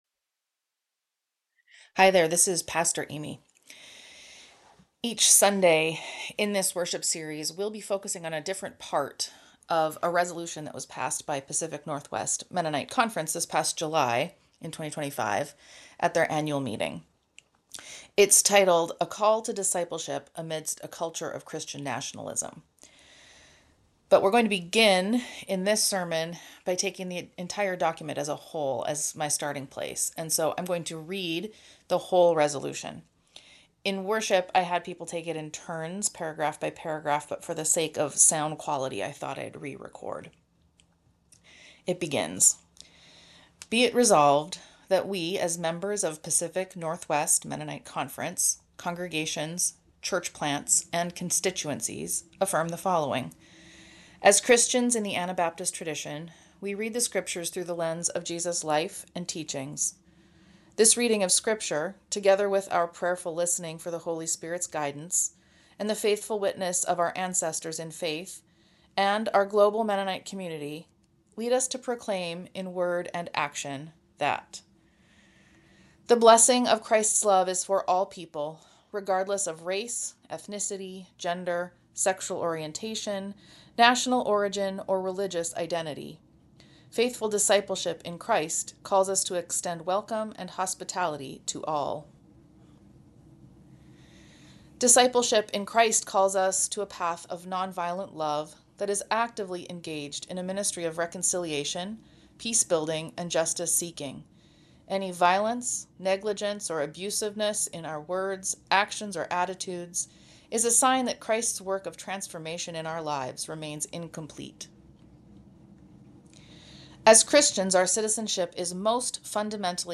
But we’re going to begin by taking the document as a whole as my starting place and so I’m going to read the entire resolution. In worship I had people take it in turns but for the sake of sound quality I thought I’d re-record.